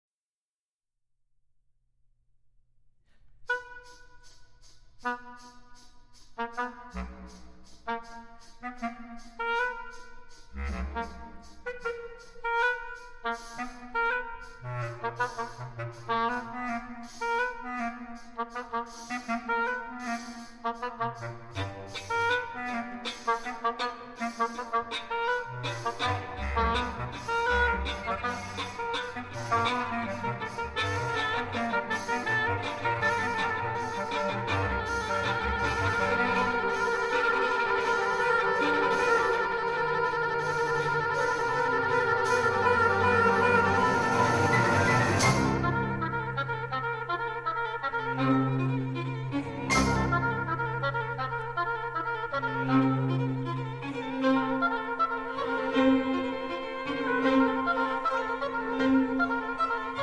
Oboe